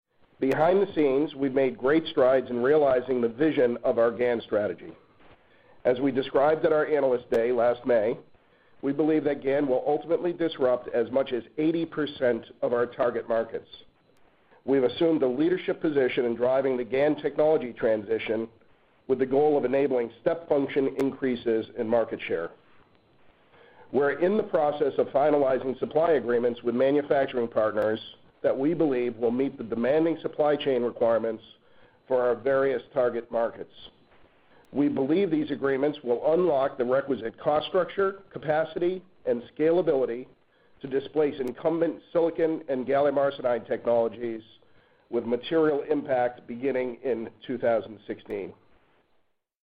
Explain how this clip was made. updated the status of the company's GaN strategy during the Q1 FY15 earnings call on 2 February 2015.